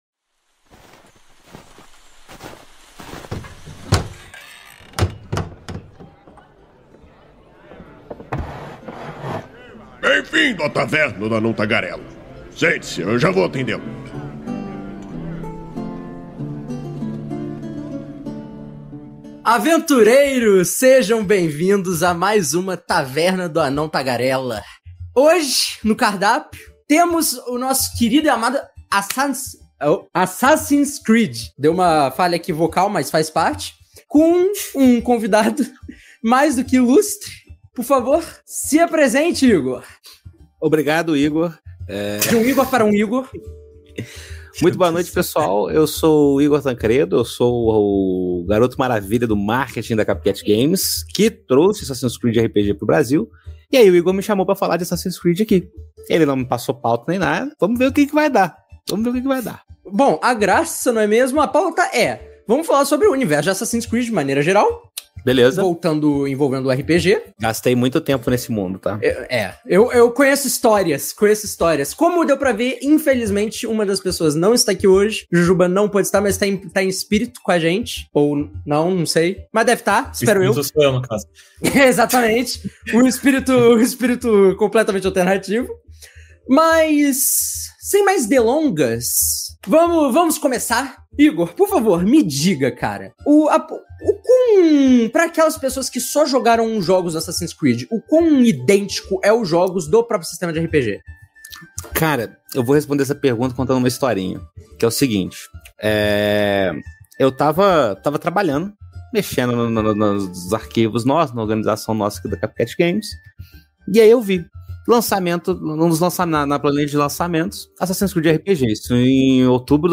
Venha entender como os diversos períodos históricos que podem ser abordados pelo jogo, saiba como algumas mecânicas icônicas dos jogos funcionam no RPG, e, por fim, tenha ideias de histórias para sua mesa. A Taverna do Anão Tagarela é uma iniciativa do site Movimento RPG, que vai ao ar ao vivo na Twitch toda a segunda-feira e posteriormente é convertida em Podcast.